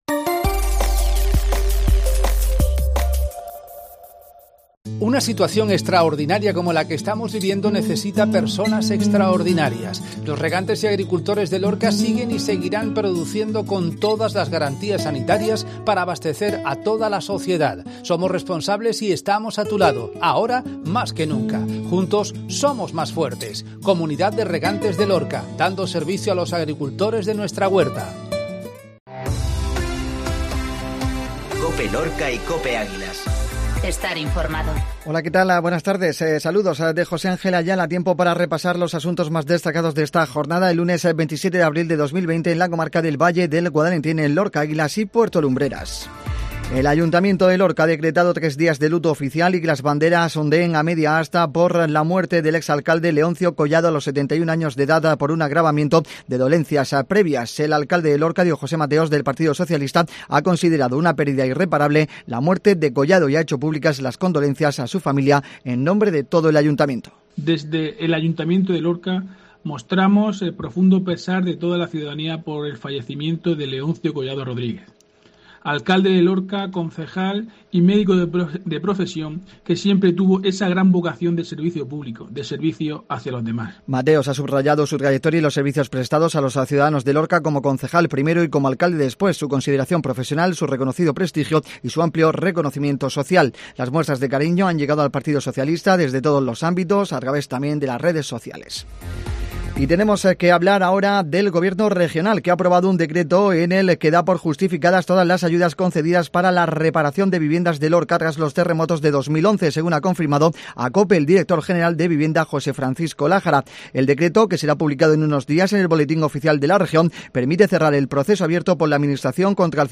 INFORMATIVO MEDIODIA COPE LUNES